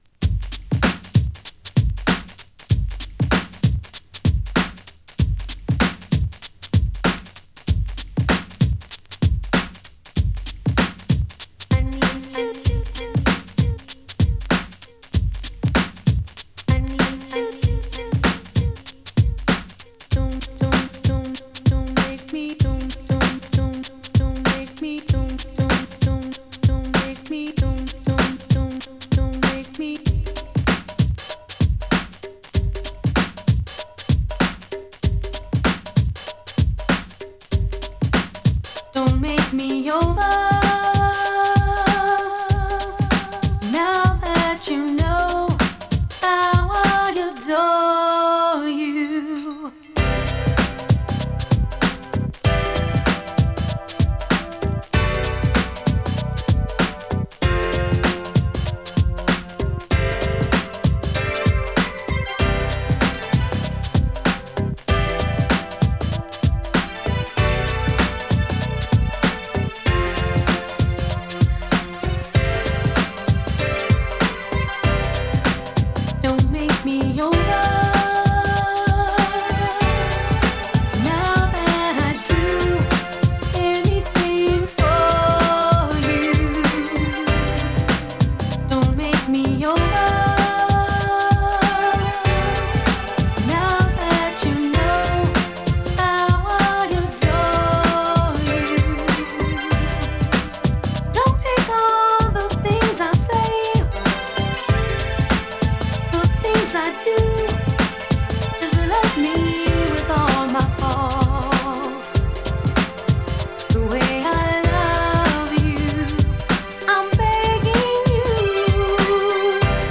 切なくもブライトな雰囲気が漂うグランドビート！ ニュージャージー出身の女性シンガーによる